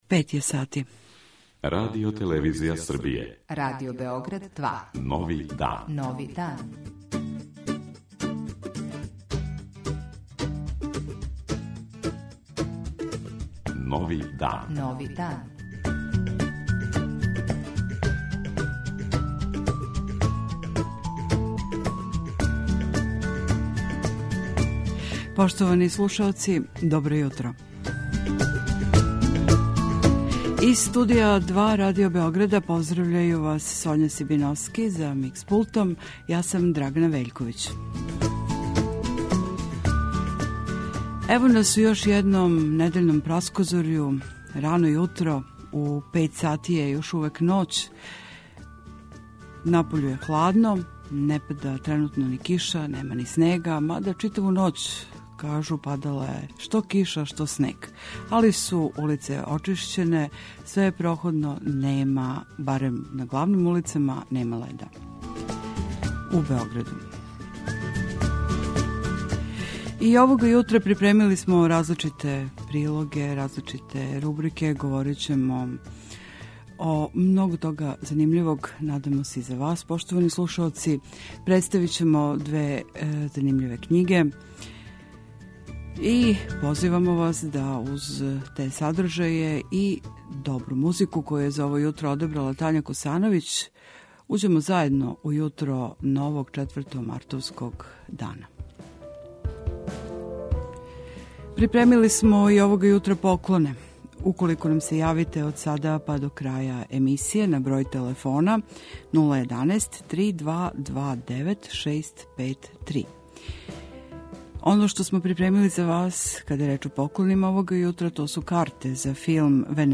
Слушаоцима који нам се јаве телефоном до краја емисије поклањамо карте за филм "Венера", чија је пројекција 8. марата у београдском Дому омладине, као и карте за представу "Деца на интернету", која се игра 7. марта, такође у ДОБ-у. Поклањамо и примерак књиге афоризама "Карлов угао 6".